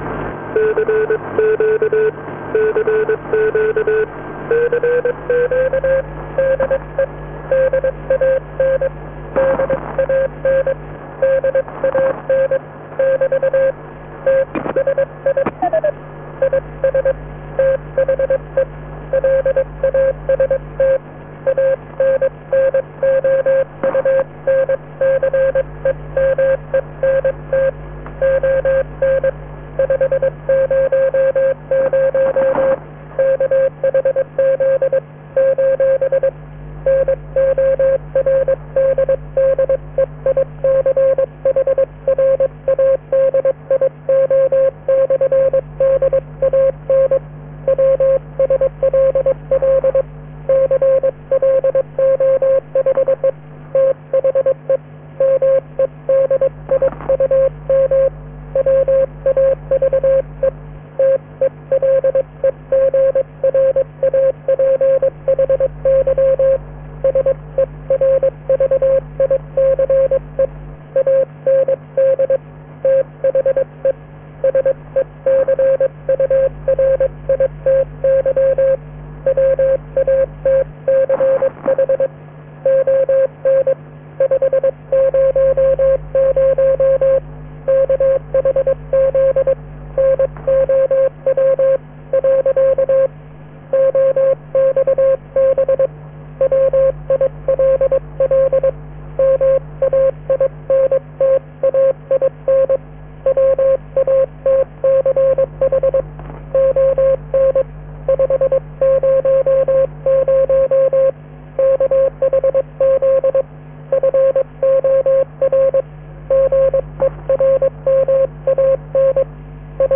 Last Transmission on 500 kHz